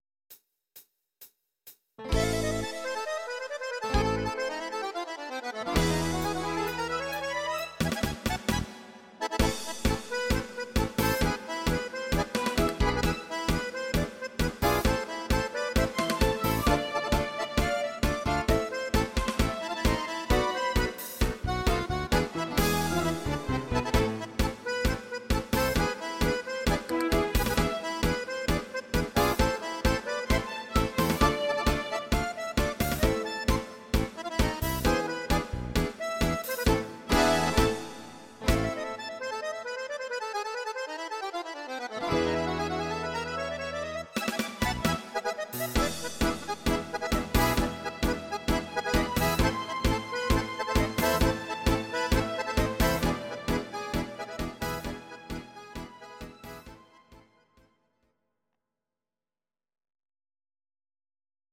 instrumental Akkordeon